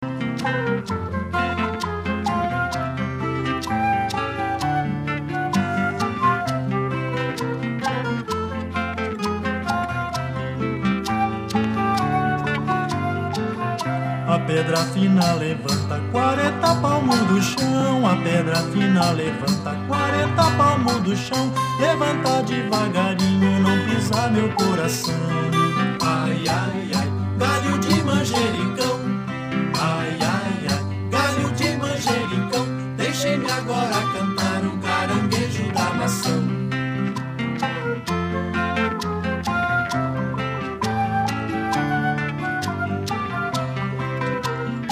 Algumas cantigas da Ilha